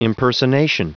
Prononciation du mot impersonation en anglais (fichier audio)
Prononciation du mot : impersonation